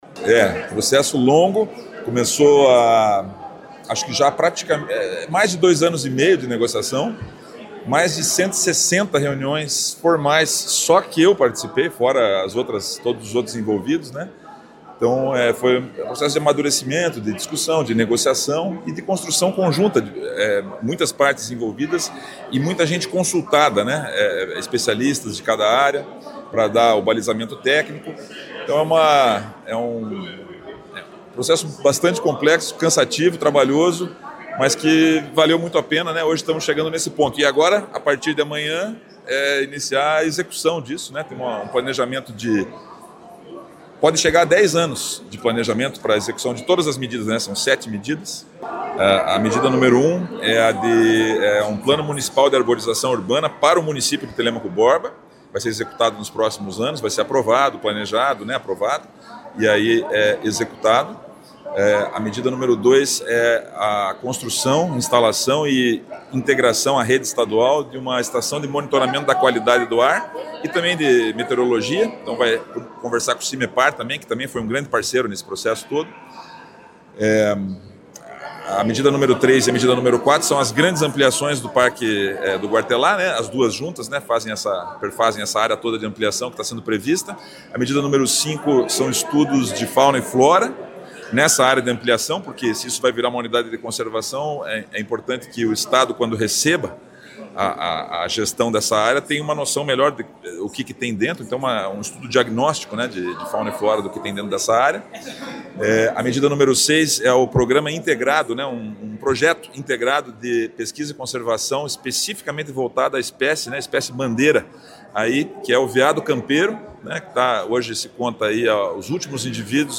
Sonora do promotor e coordenador regional do Gaema em Ponta Grossa, Fábio Grade, sobre o acordo para ampliação do Parque Guartelá